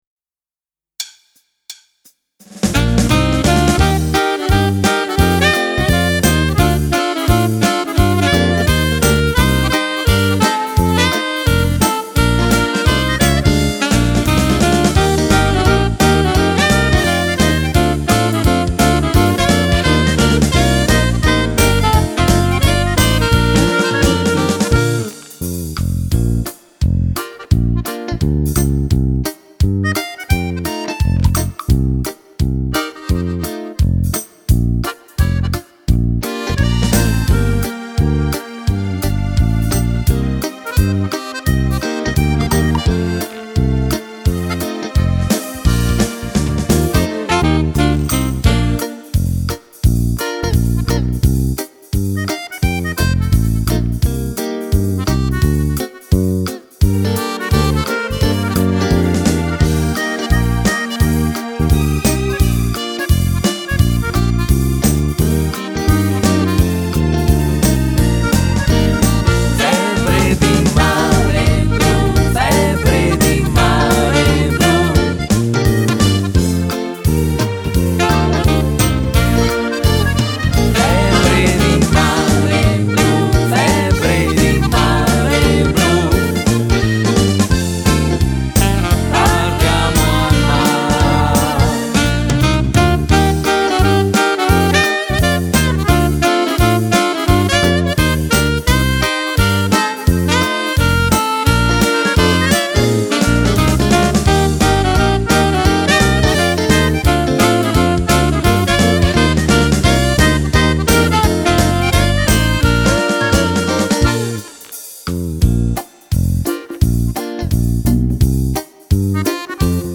Fox trot
Uomo